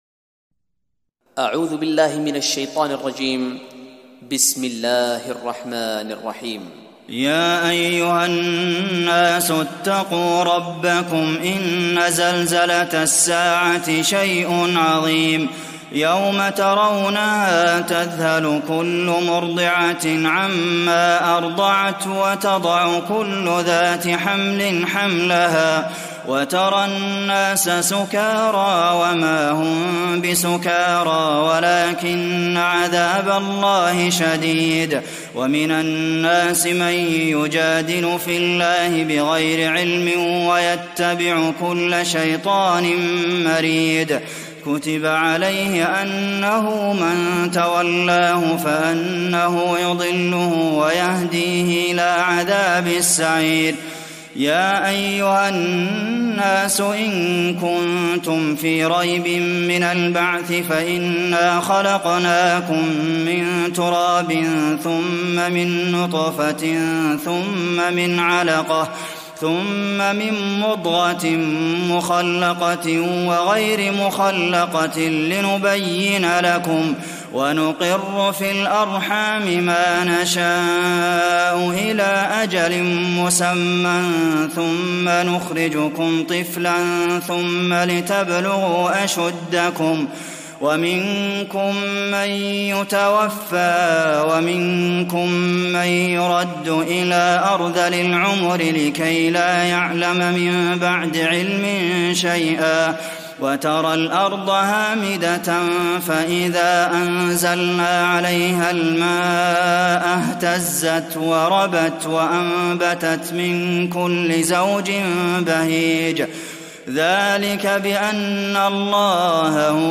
تراويح رمضان 1419هـ سورة الحج كاملة Taraweeh Ramadan 1419H from Surah Al-Hajj > تراويح الحرم النبوي عام 1419 🕌 > التراويح - تلاوات الحرمين